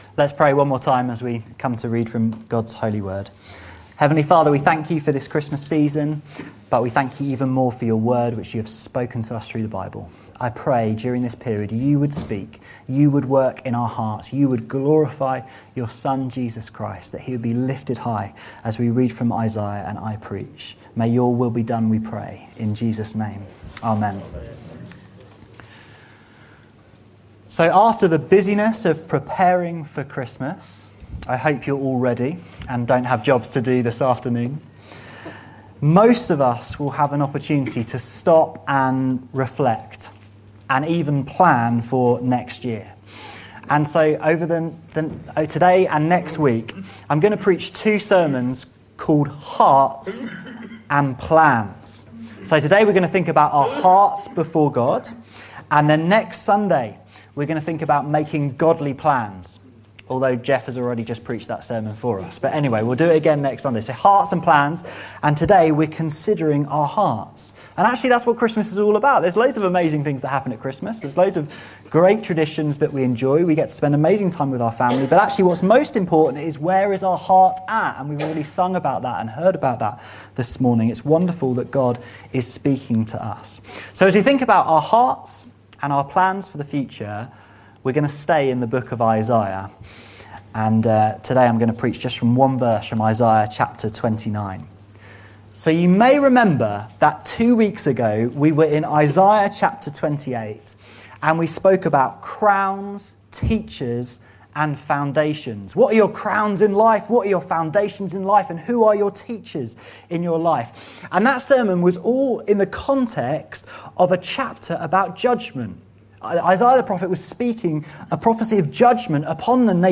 This sermon encourages us to live lives where our hearts are close to and fully committed to God. For Jesus came so that we could draw near to God in our hearts and know him intimately. The Holy Spirit helps us live lives like this.